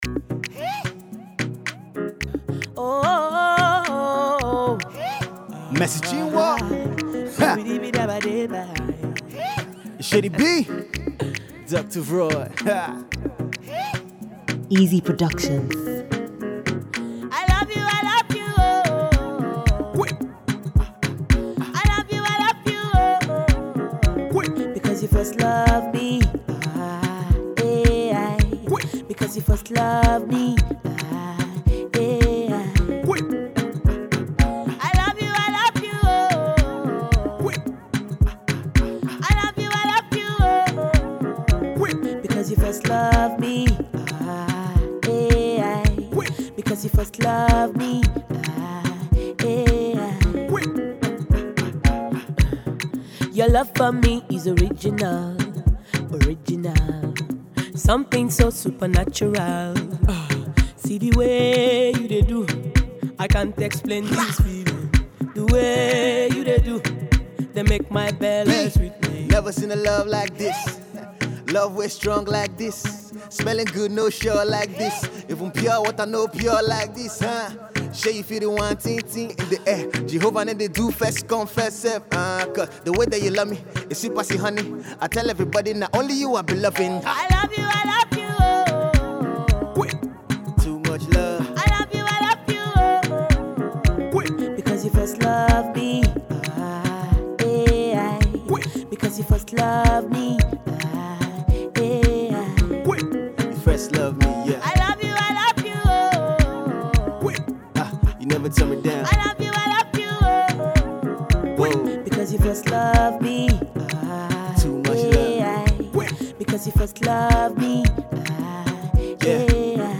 Dynamic gospel singer-songwriter
mid-tempo steady rhythmic Afropop tune
Ghanaian high-Life flavour